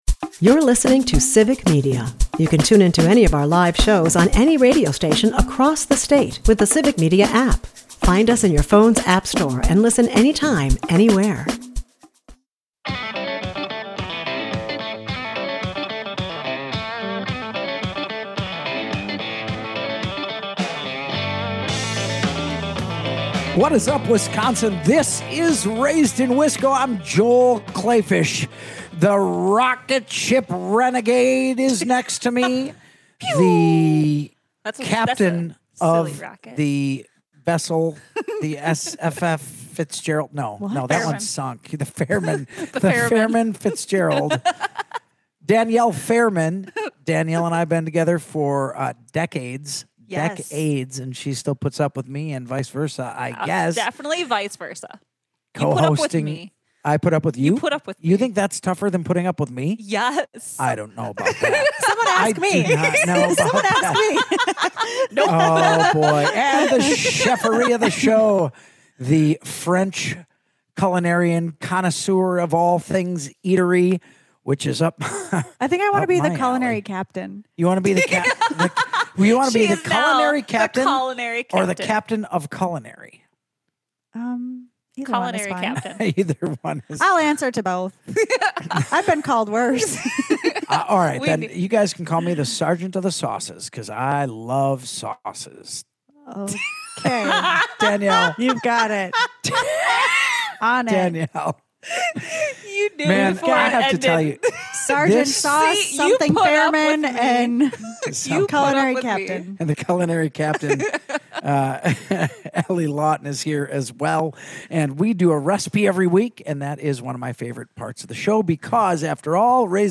interview with a very special guest